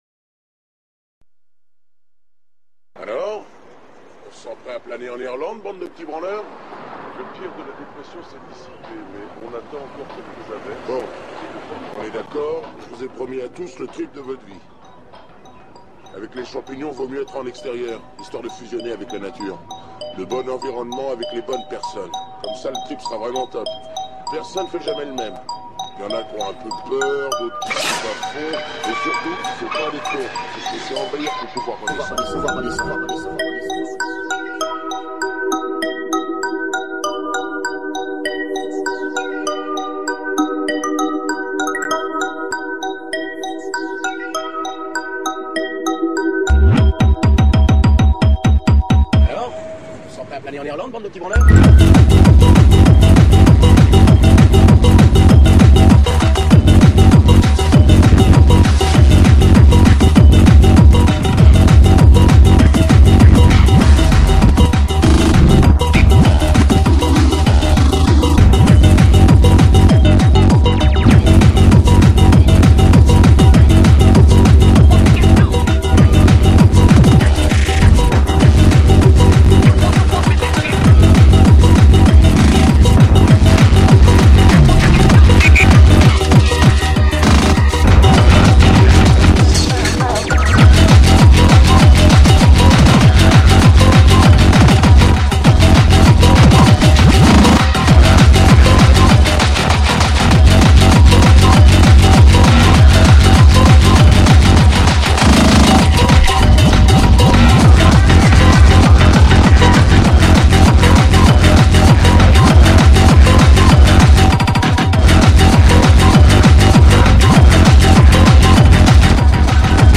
hardtek